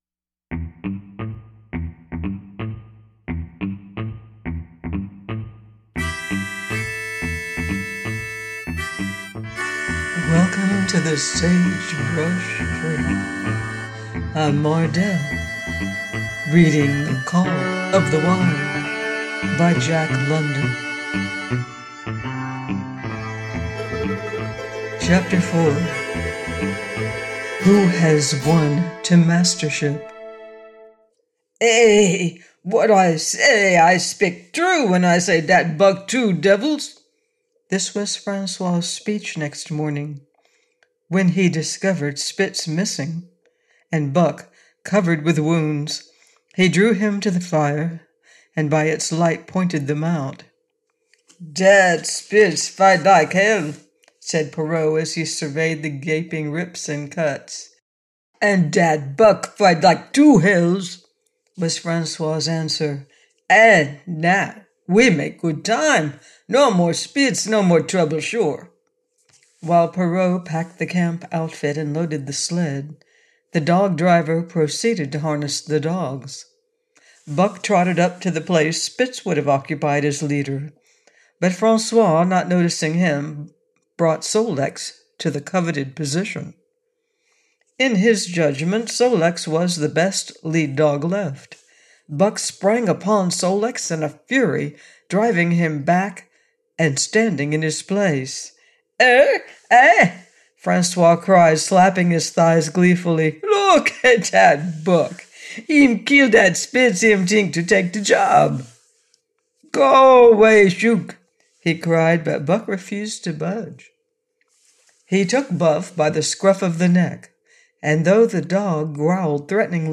The Call Of The Wild: by Jack London - AUDIOBOOK